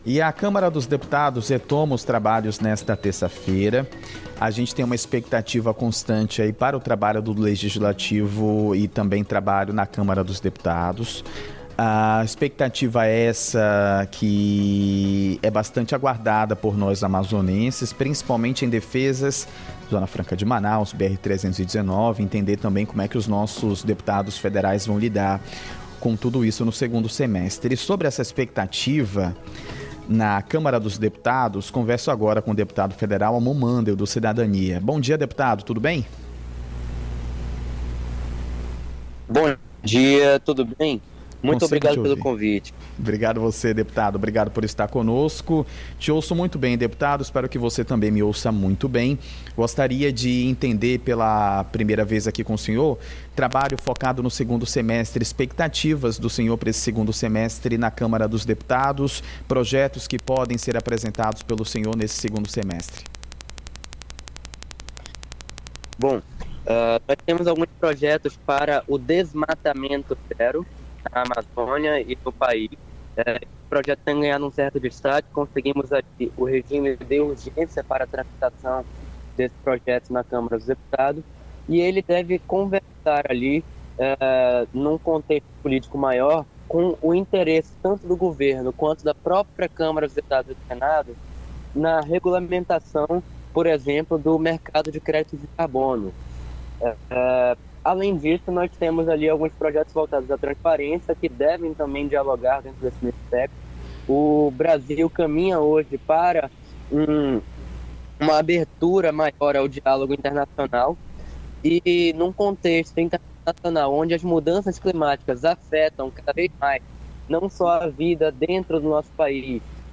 ENTREVSITA_AMON_MANDEL_010823.mp3